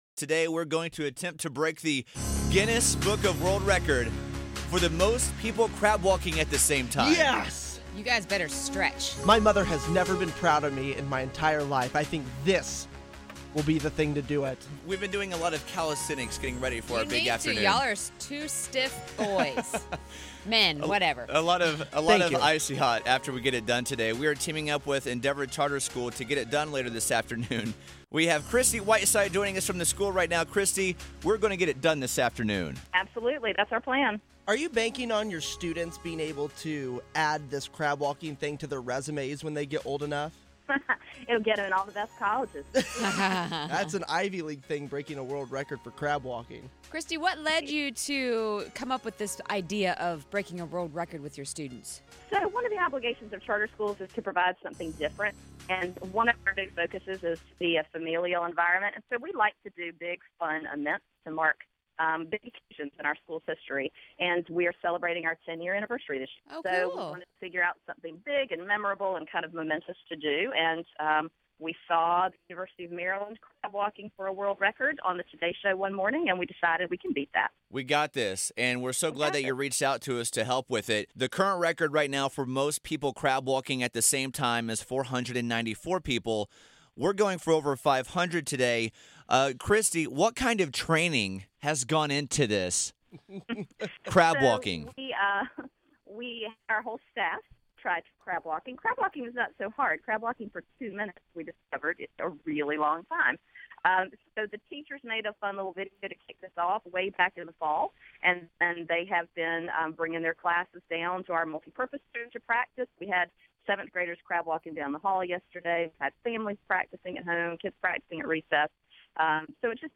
Radio Coverage of the Crab Walk